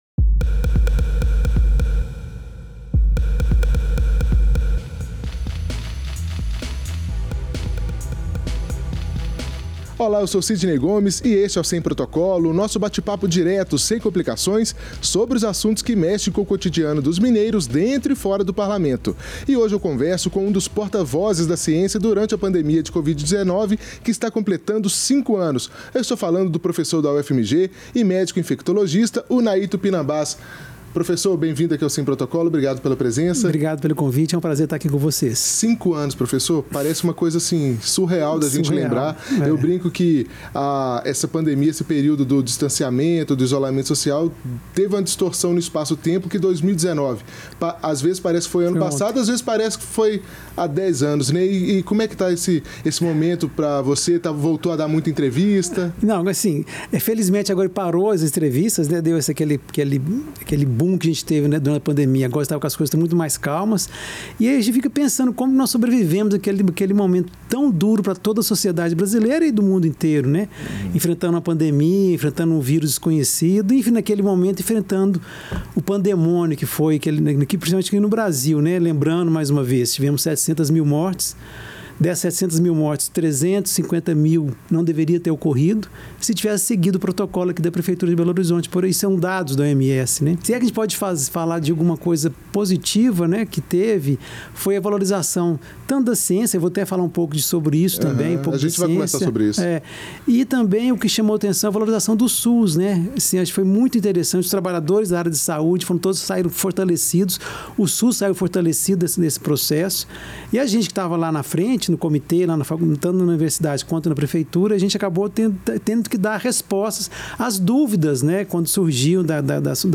Na conversa